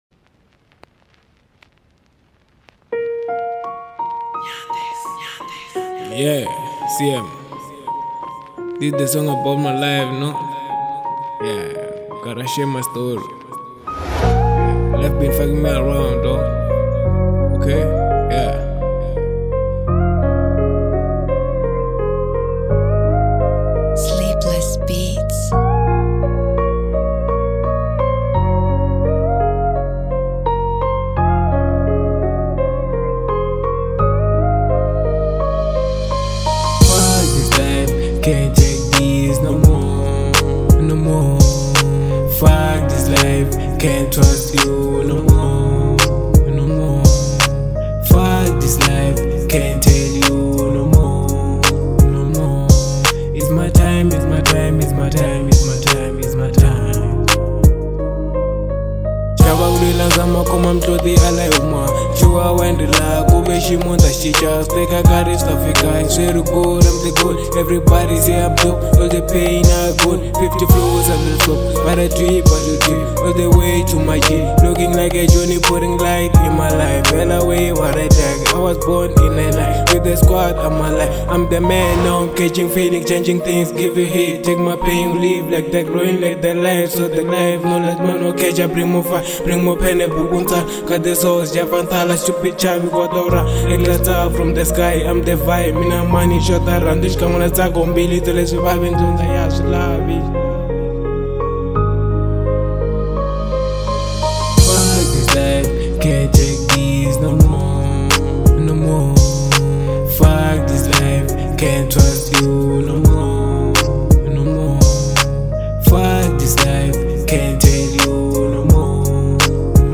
02:57 Genre : Hip Hop Size